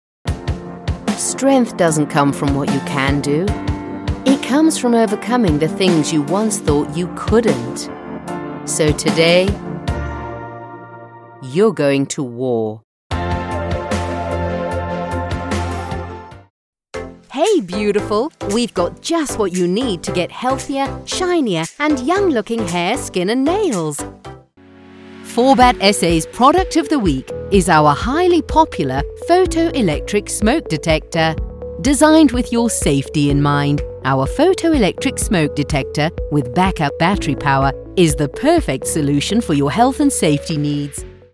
Kommerzielle Demo
Meine Stimme ist natürlich und freundlich, aber auch vollmundig und bestimmend.
Audio Technica AT2020 Mikrofon